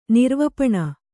♪ nirvapaṇa